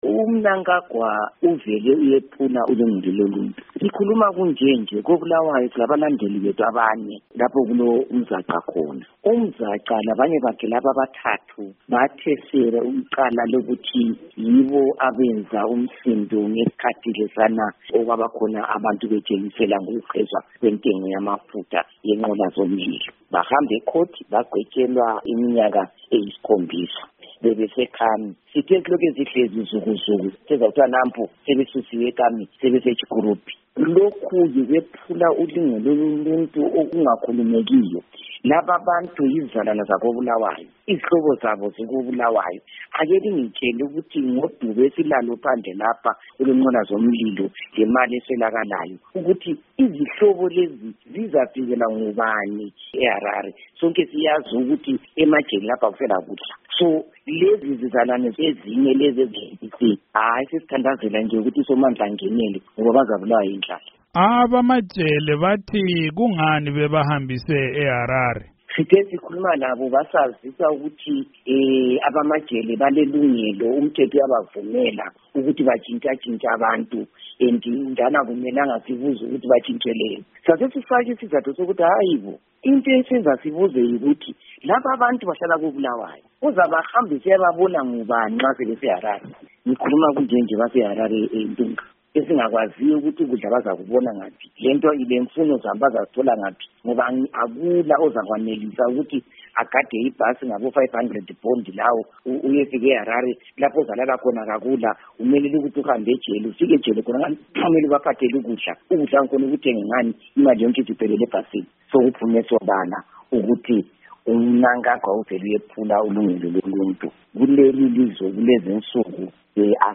Ingxoxo loNkosazana Thabitha Khumalo